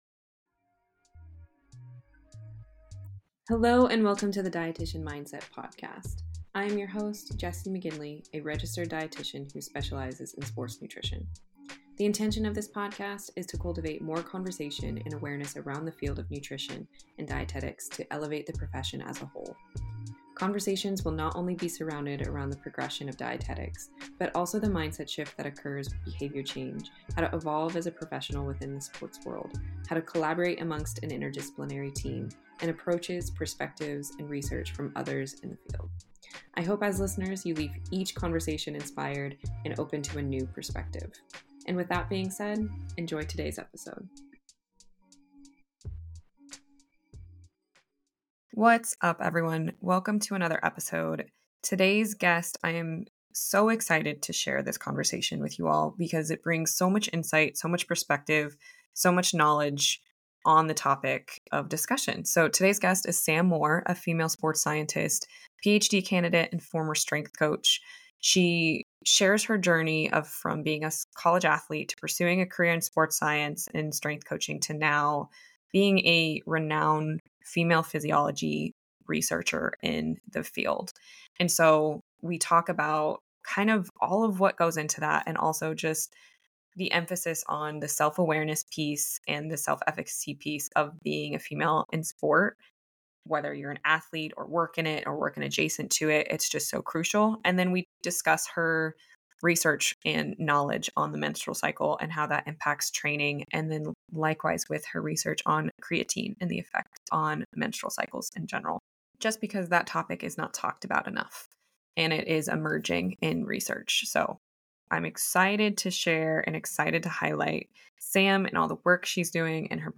The conversation explores the lack of research and education around the menstrual cycle and its impact on female athletes. It emphasizes the importance of self-awareness and understanding the different phases of the menstrual cycle.